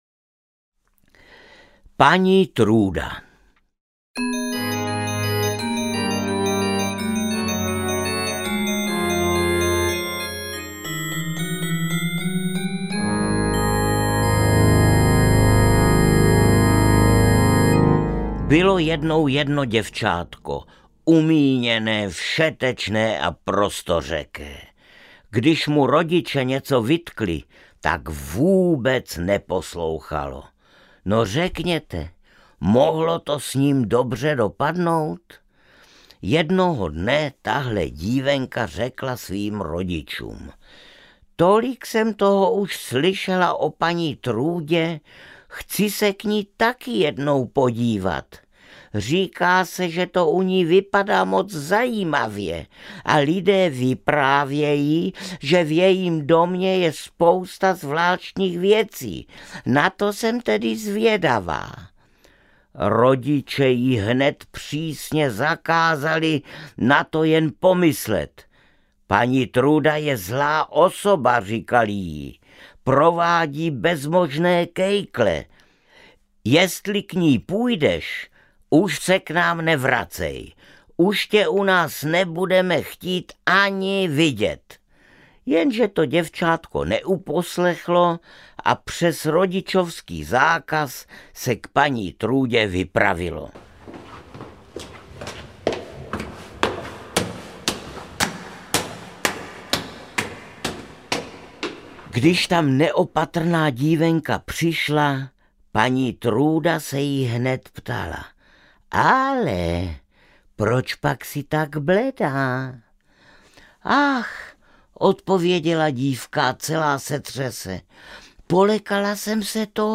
Pohádky bratří Grimmů audiokniha
Ukázka z knihy